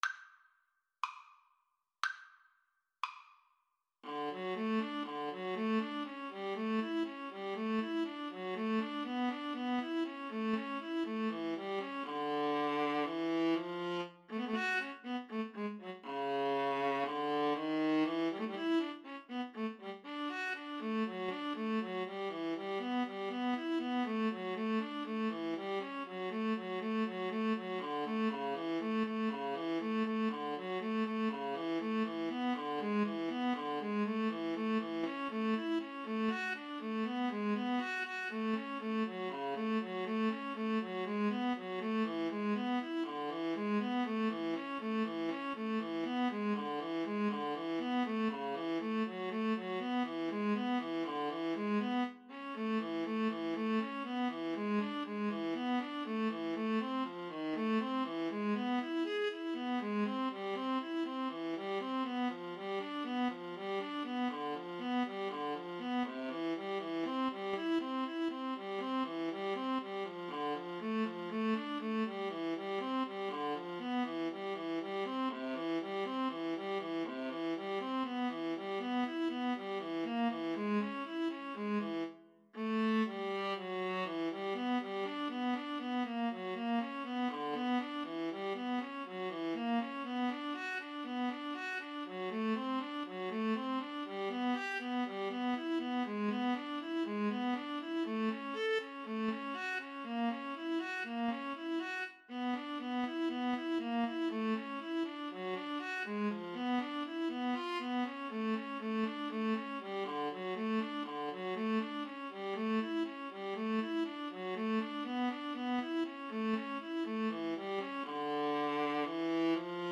adagio Slow =c.60
Classical (View more Classical Viola Duet Music)